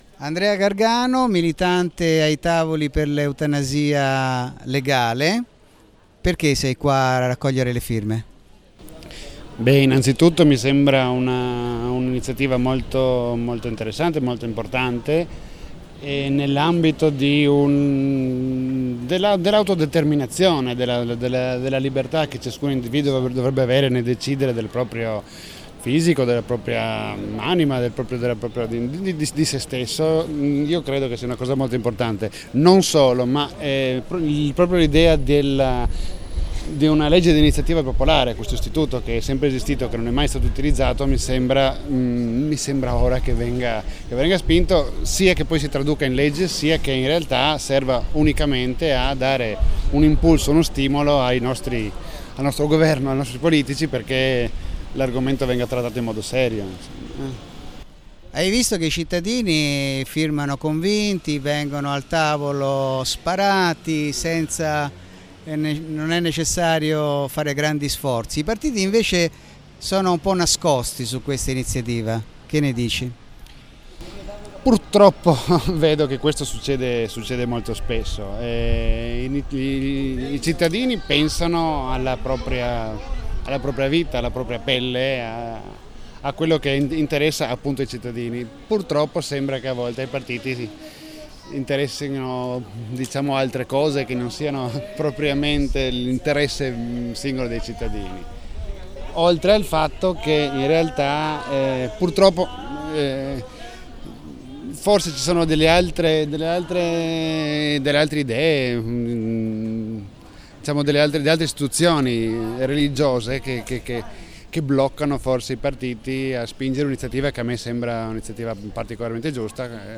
Trieste – Eutanasia Legale – Interviste ai tavoli (22/06/2013)